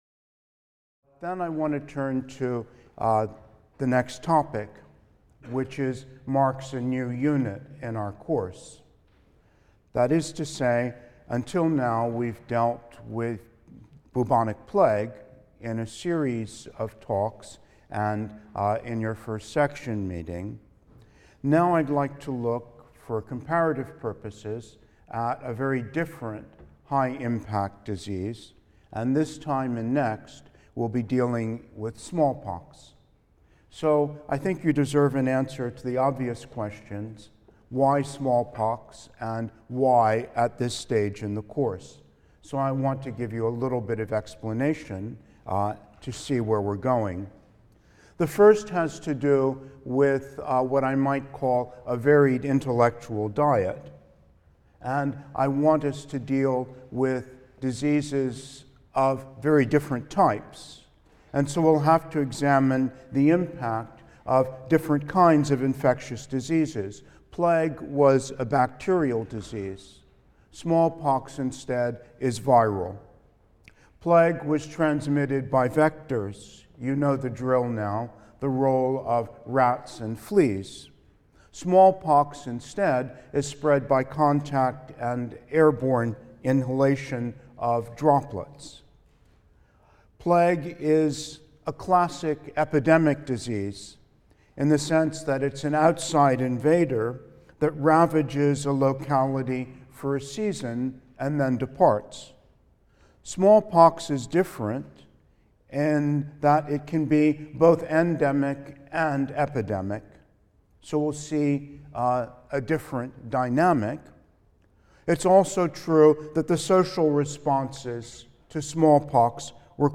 HIST 234 - Lecture 6 - Smallpox (I): “The Speckled Monster” | Open Yale Courses